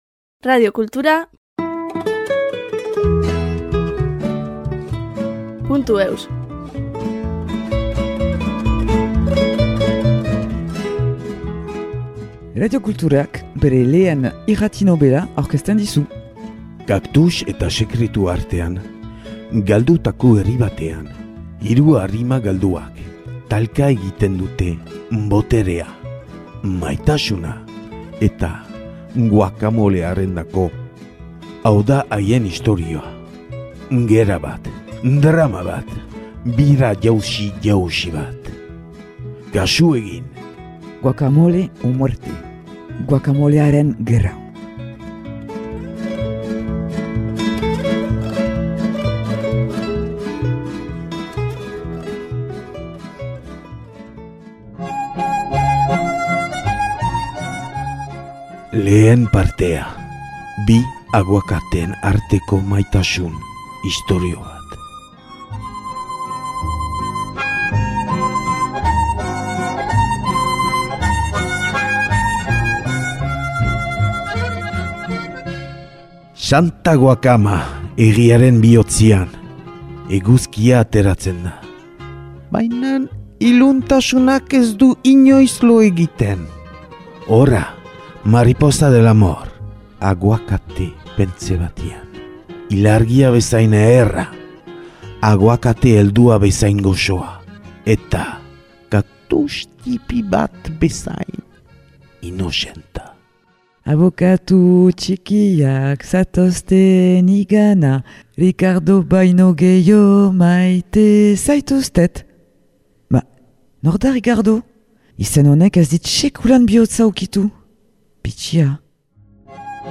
Radiokulturak bere lehen irrati-nobela aurkezten dizu : Kaktus eta sekretuen artean galdutako herri batean, hiru arima galduek talka egiten dute boterea, maitasuna … eta guacamolearendako !